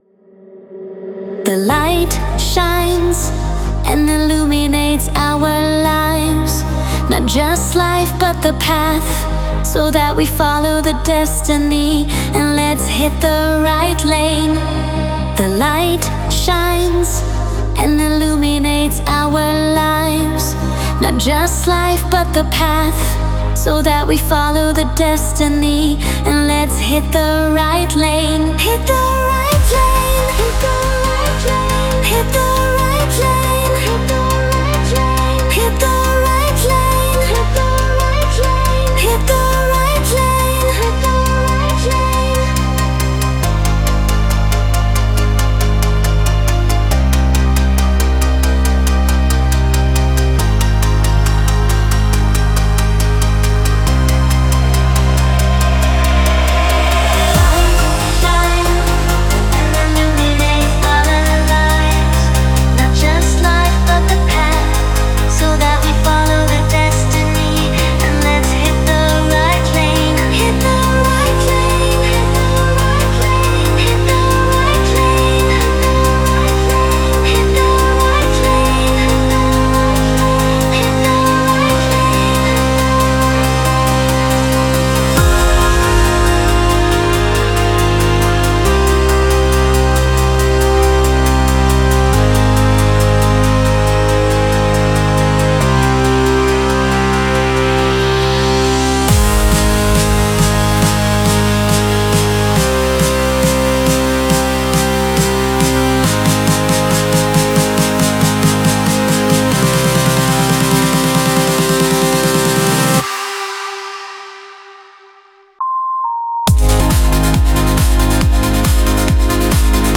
ELETRÔNICAS